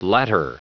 Prononciation du mot latter en anglais (fichier audio)
Prononciation du mot : latter